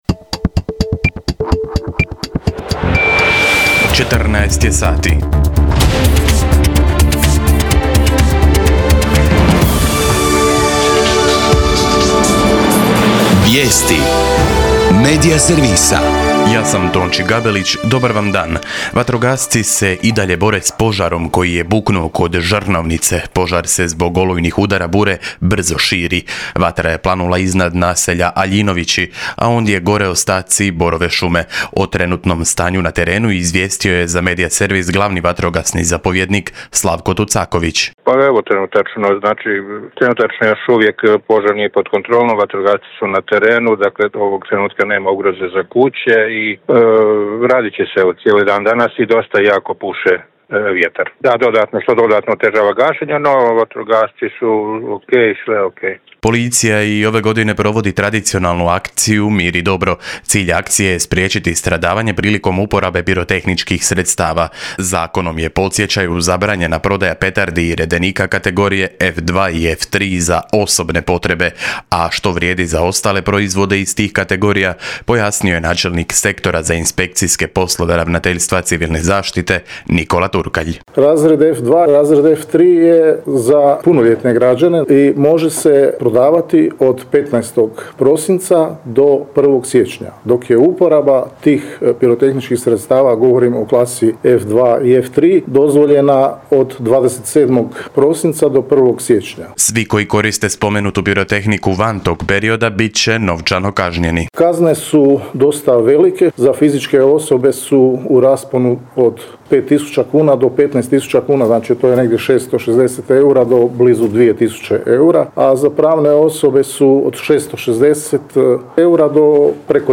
VIJESTI U 14